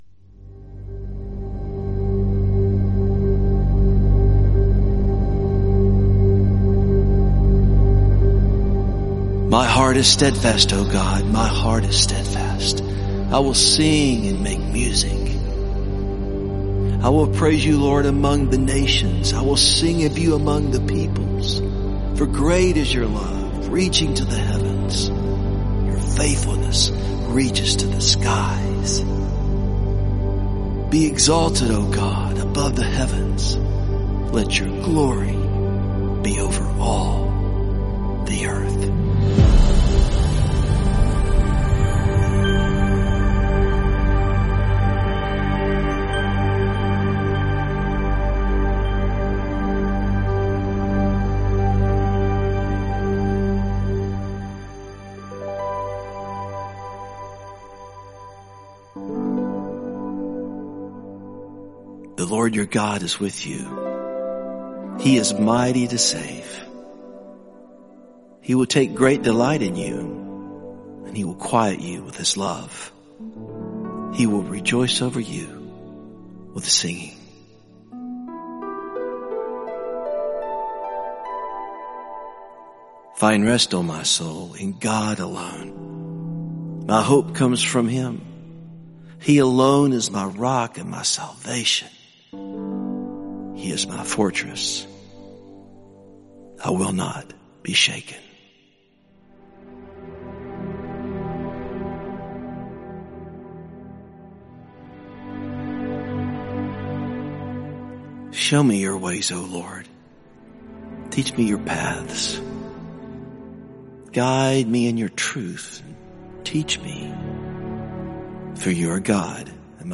calming, introspective, and soothing instrumental music